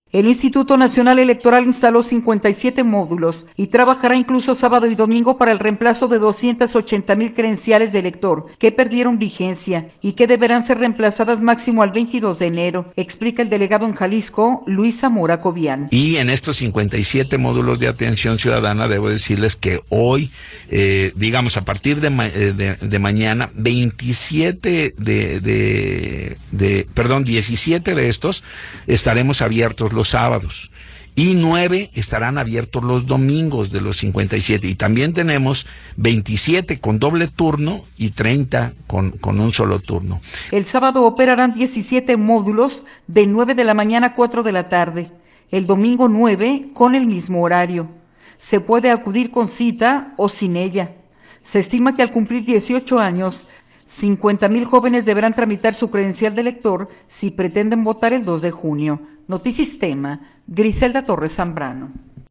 El Instituto Nacional Electoral instaló 57 módulos y trabajará incluso sábado y domingo para el remplazo de 280 mil credenciales de elector que perdieron vigencia y que deberán ser remplazadas máximo al 22 de enero, explica el delegado en Jalisco, Luis Zamora Cobián.